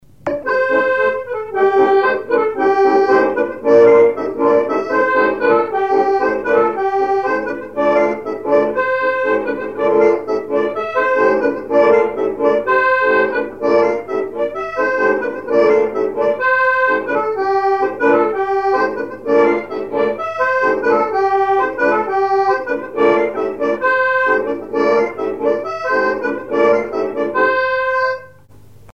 branle
Chants brefs - A danser
instrumentaux à l'accordéon diatonique
Pièce musicale inédite